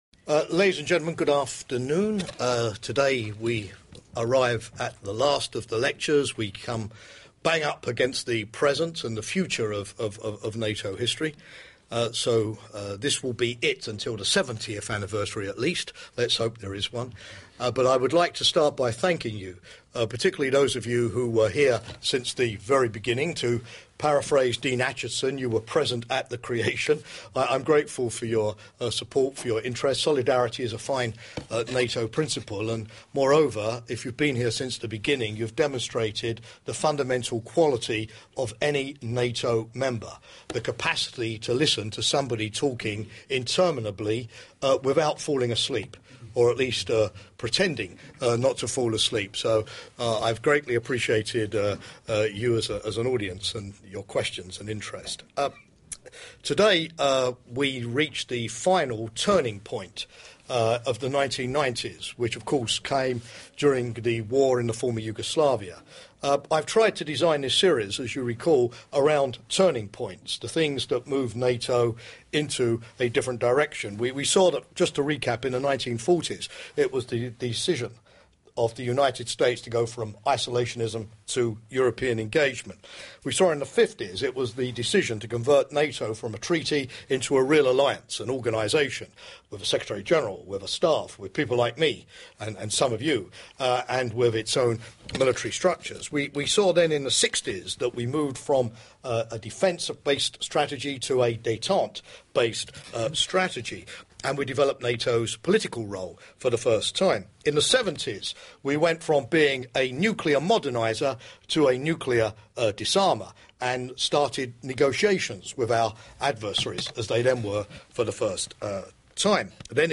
1979: The Soviet Union deploys its SS20 missiles This fifth lecture in the six-part series, Jamie's History Class, explores how NATO survived the challenges of a post-Cold War Europe.